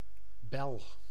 Ääntäminen
IPA: [bɛl]